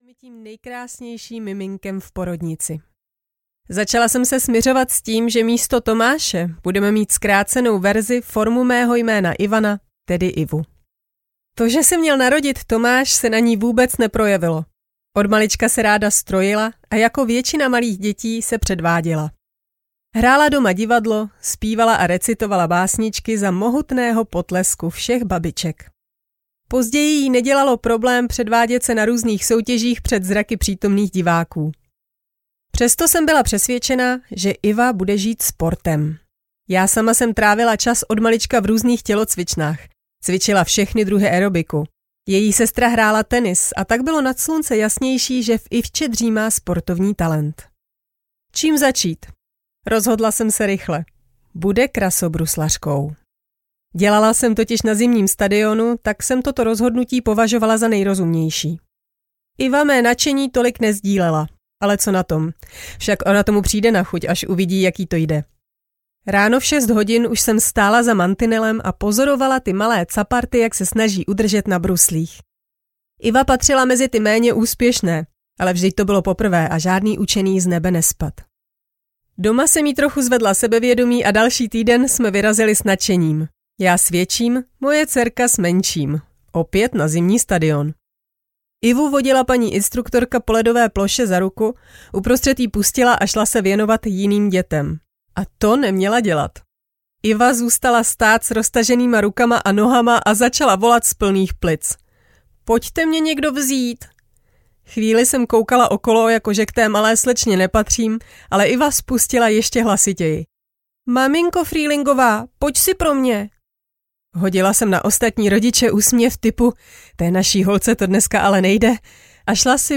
Ukázka z knihy
• InterpretIva Frühlingová